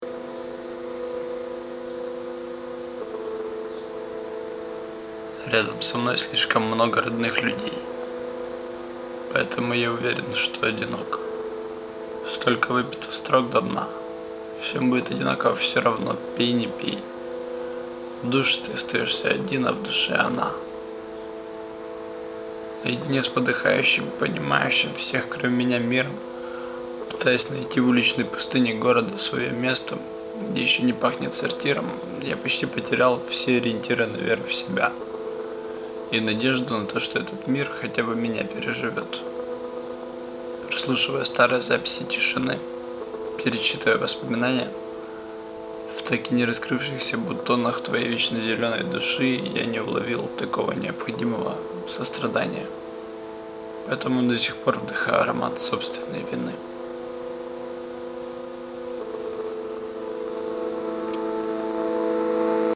не)) то я захворал и воспользовался "чужим" голосом